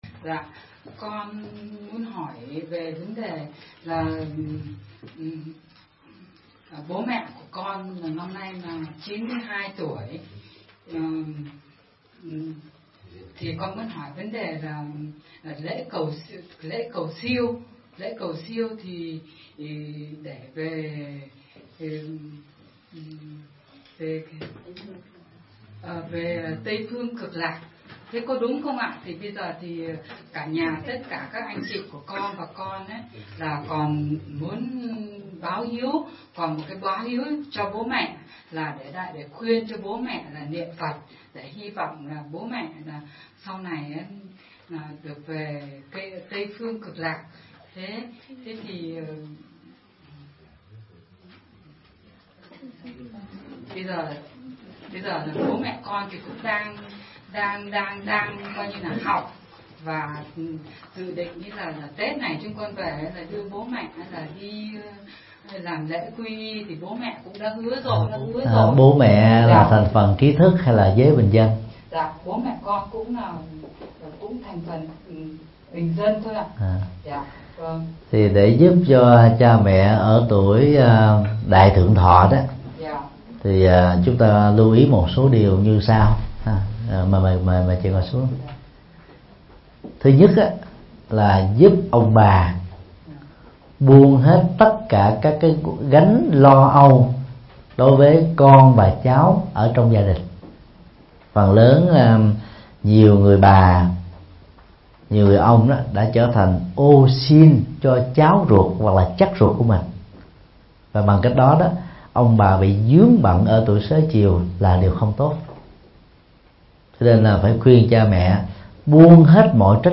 Vấn đáp: Giúp bố mẹ tuổi già – Thích Nhật Từ